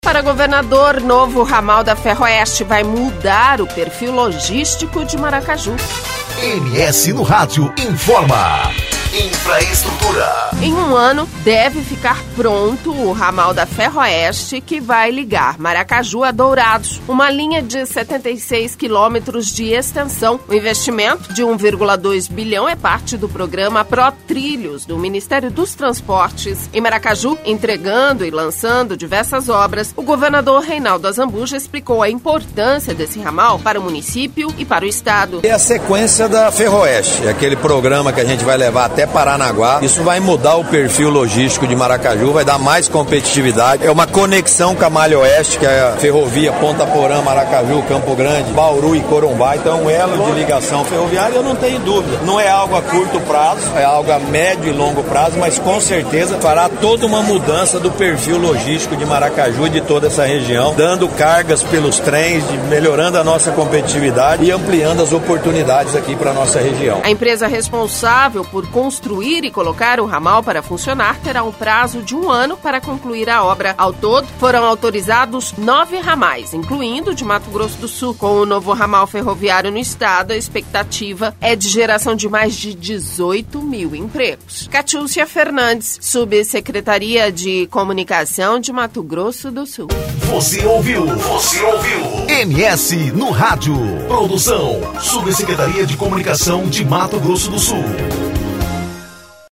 Em Maracaju, entregando e lançando diversas obras, o governador Reinaldo Azambuja explicou a importância desse ramal para o município e para o Estado.